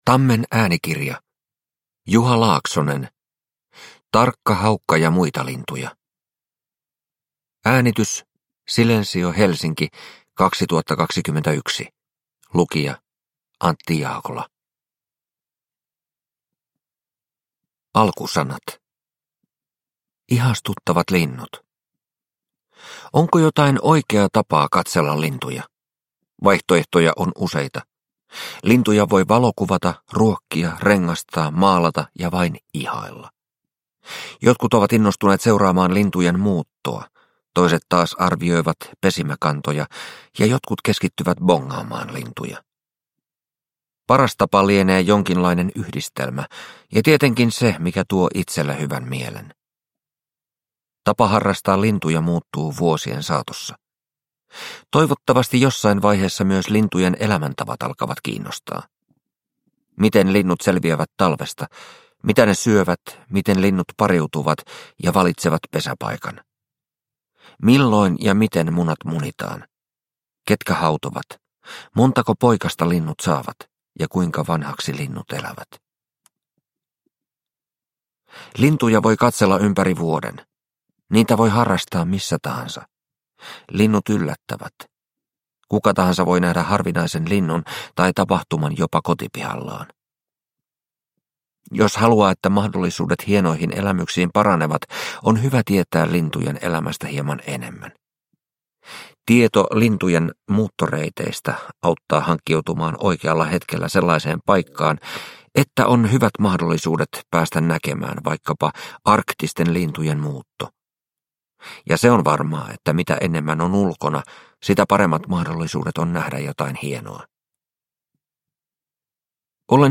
Tarkka haukka ja muita lintuja – Ljudbok – Laddas ner